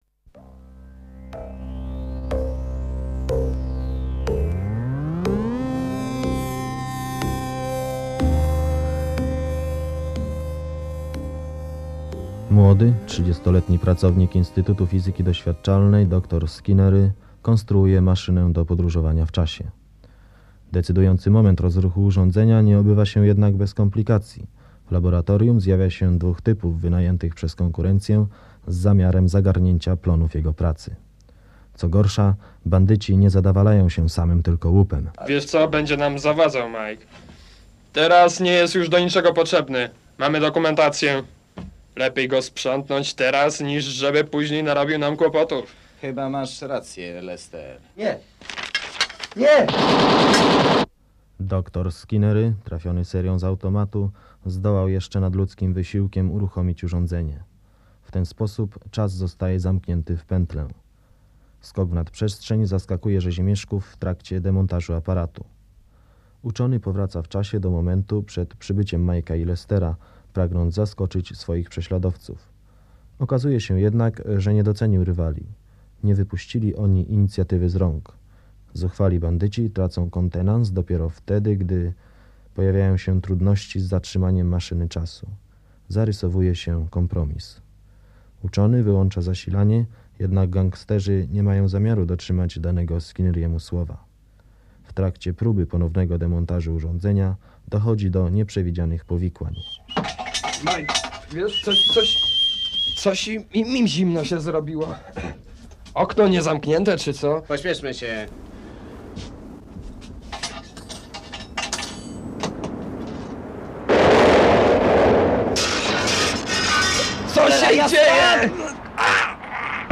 Słuchowisko fantastyczne